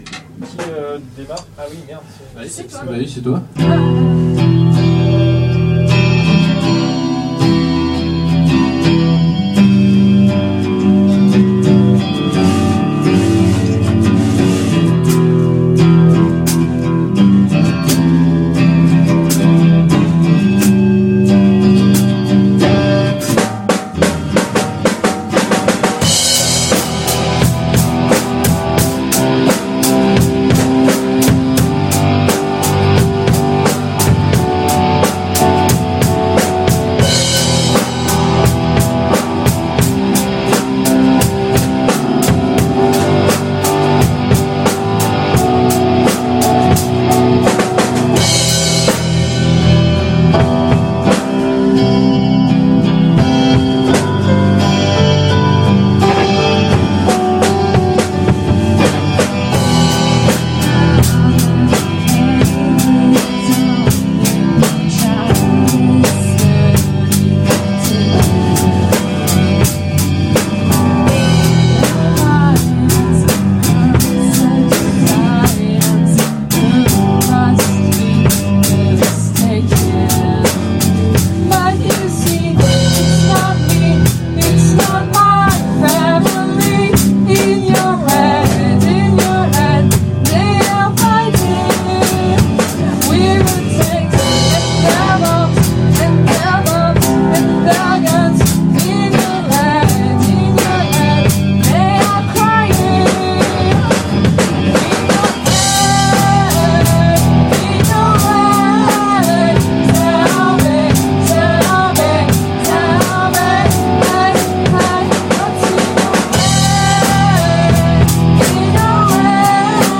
Reprise
switch batteur-guitariste